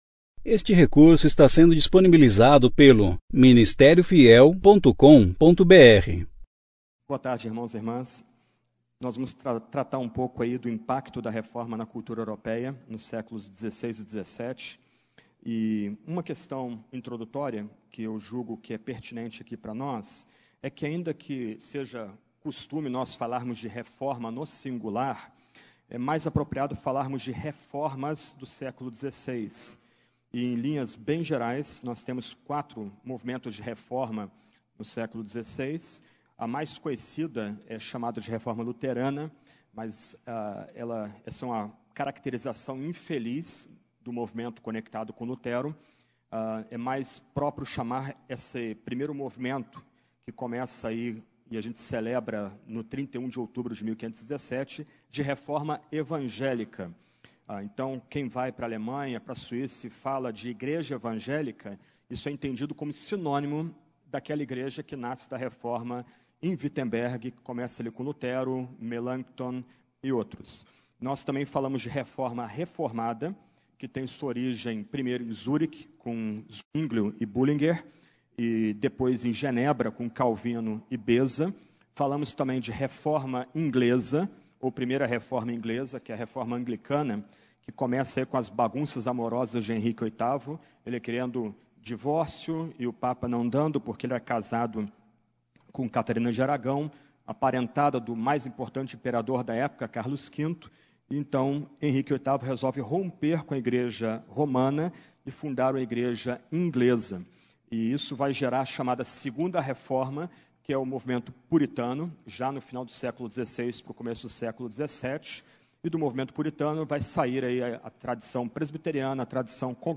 Conferência: 33ª Conferência Fiel para Pastores e Líderes - Brasil Tema: Protestantes Ano: 2017 Mens